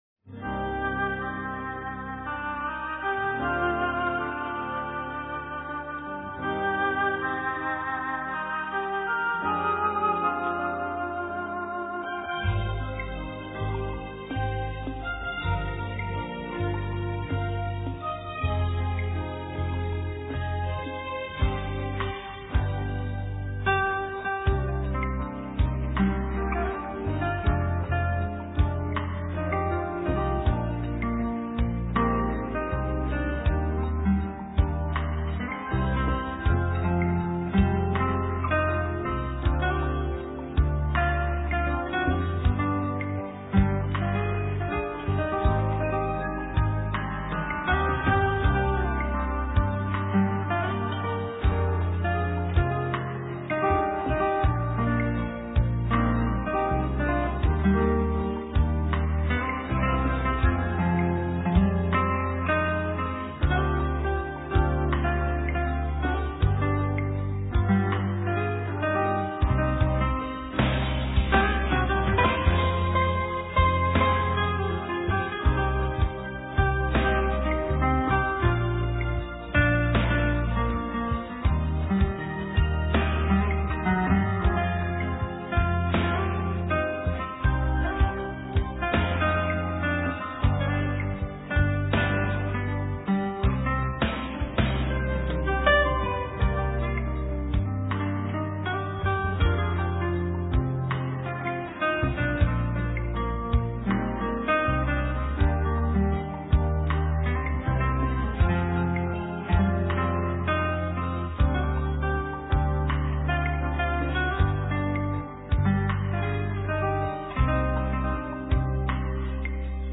* Thể loại: Việt Nam